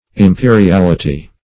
imperiality - definition of imperiality - synonyms, pronunciation, spelling from Free Dictionary
Imperiality \Im*pe`ri*al"i*ty\, n.; pl. Imperialities.